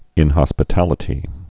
(ĭnhŏs-pĭ-tălĭ-tē)